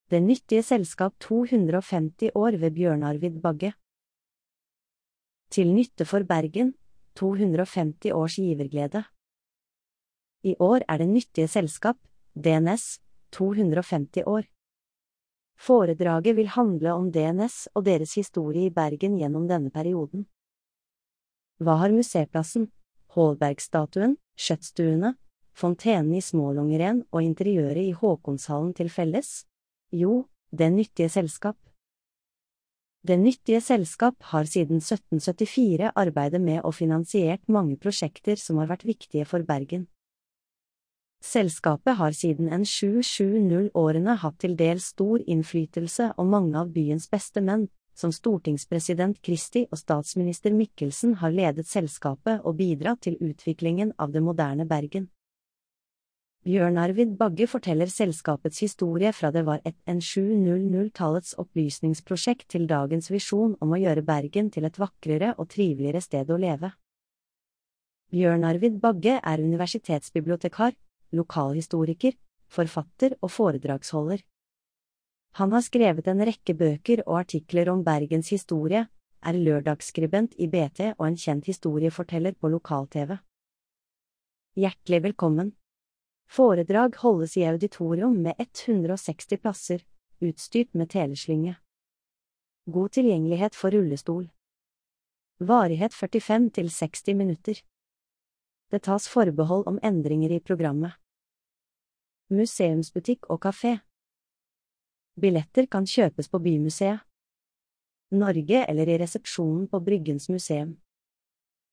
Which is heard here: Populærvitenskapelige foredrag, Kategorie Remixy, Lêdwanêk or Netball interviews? Populærvitenskapelige foredrag